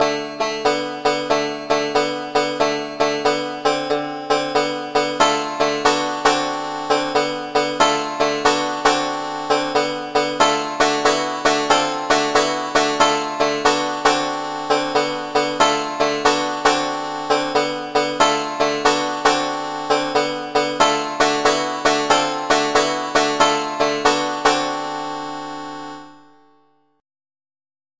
channel 3 is patch 105, which is a banjo